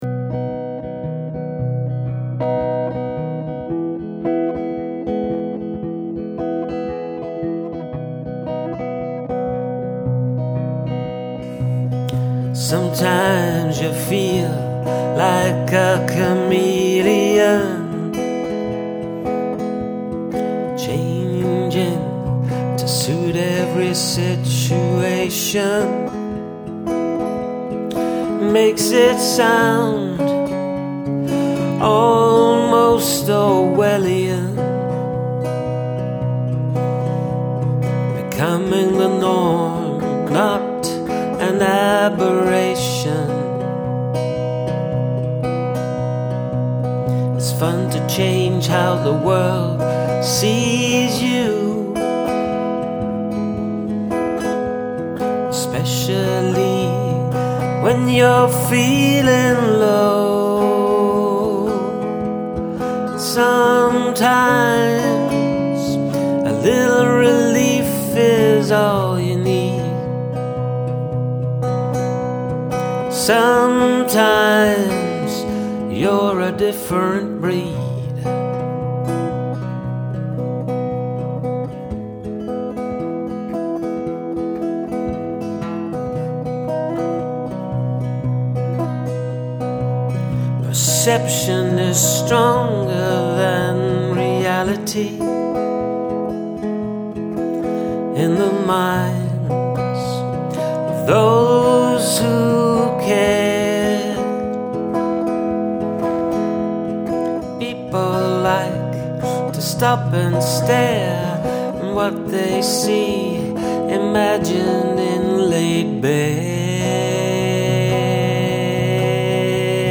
Lovely tone on the guitar, fantastic lyrics as well.
love the gtr tone and again good vox, great lyrics again